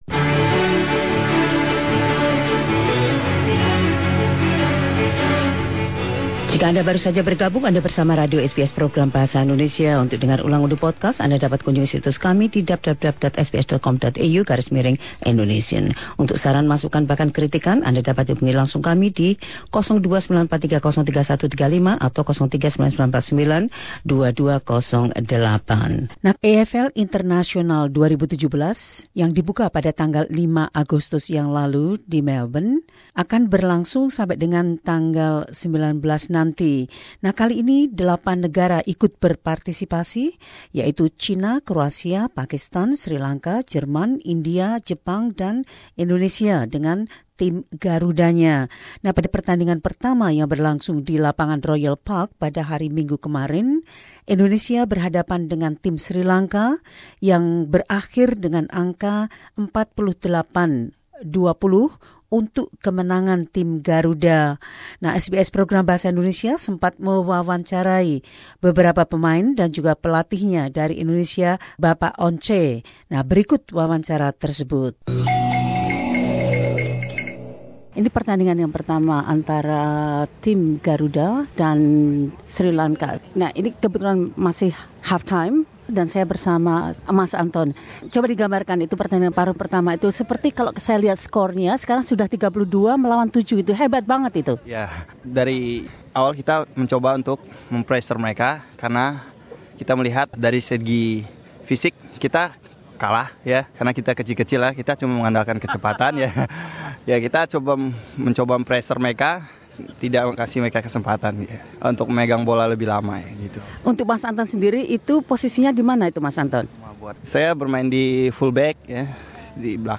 Anggota tim Garuda menjelaskan.